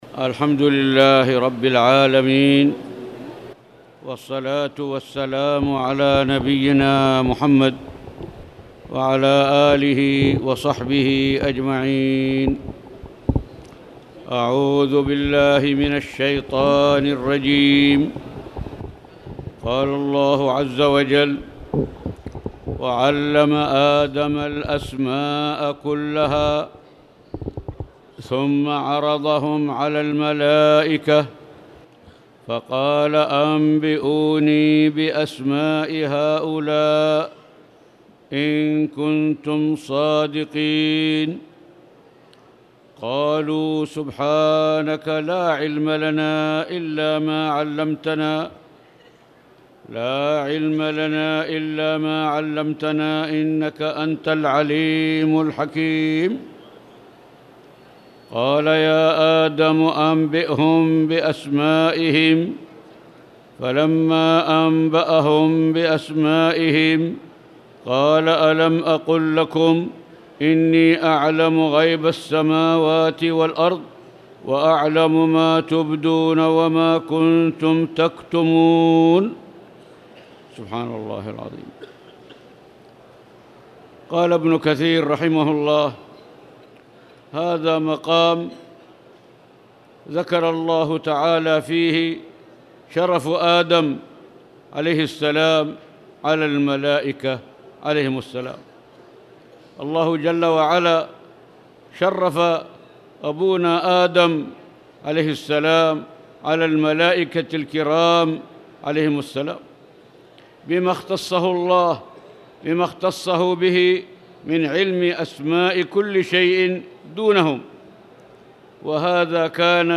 تاريخ النشر ٢٨ ذو الحجة ١٤٣٧ هـ المكان: المسجد الحرام الشيخ